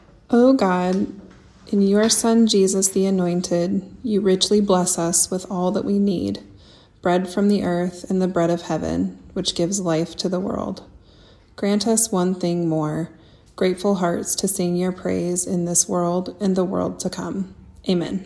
Benediction